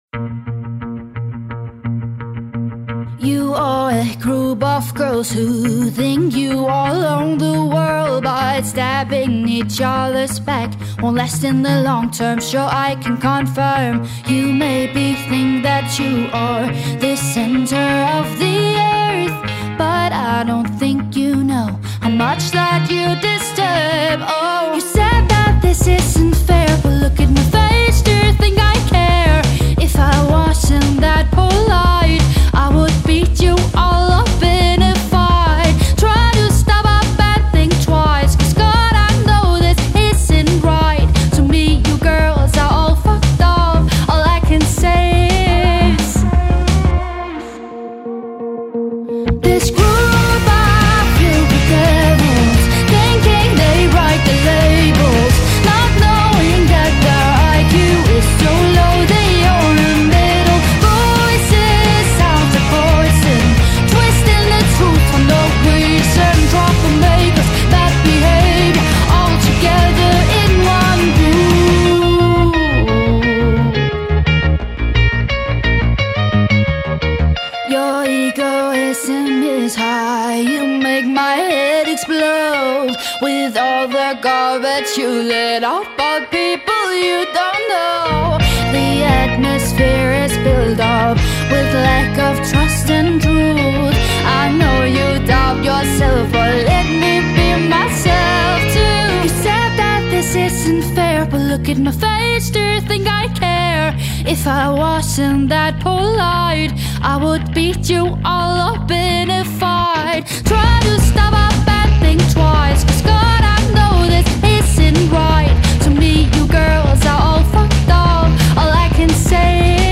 • Indie
• Pop
• Singer/songwriter